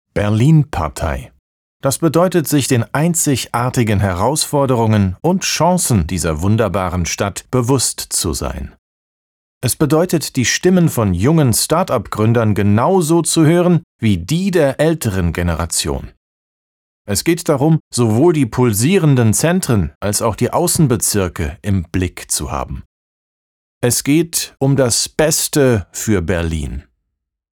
Alemán
Anuncios políticos